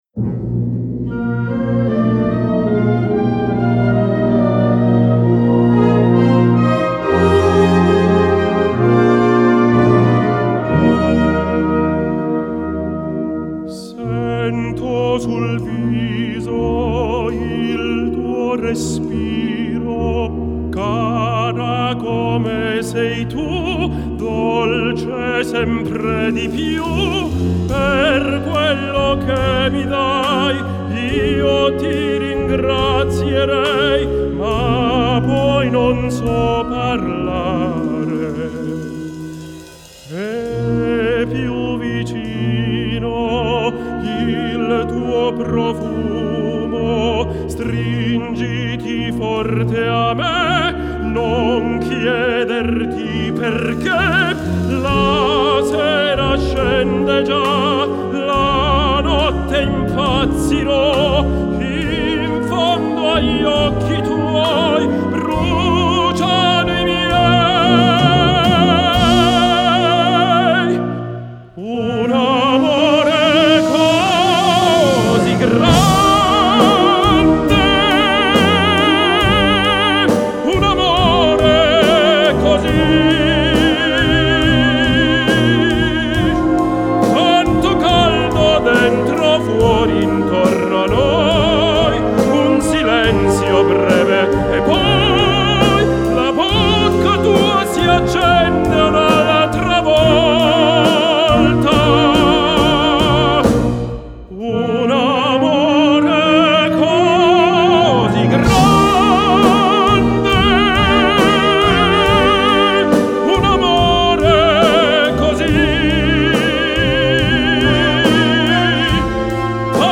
Gattung: Solo Gesang (oder Instrumental) und Blasorchester
Besetzung: Blasorchester